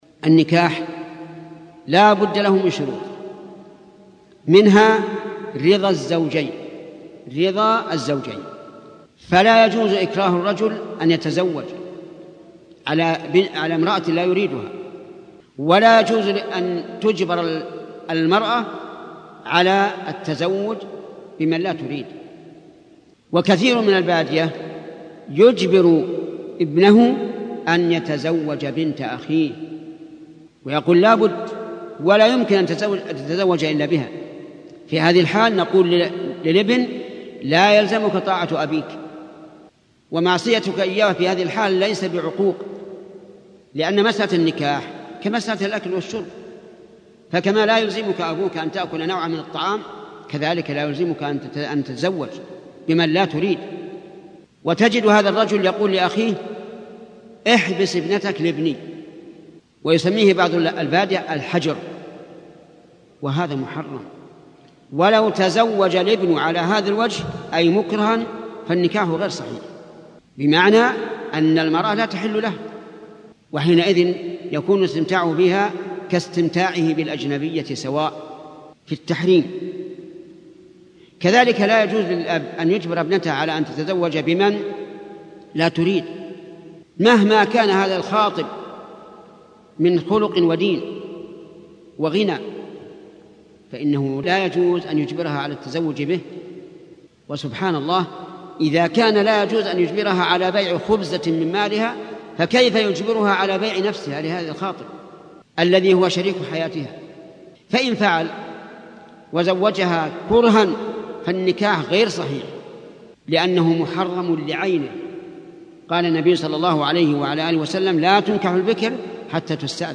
شبكة المعرفة الإسلامية | الدروس | من أهم شروط النكاح |محمد بن صالح العثيمين
من أهم شروط النكاح | الشيخ : محمد بن صالح العثيمين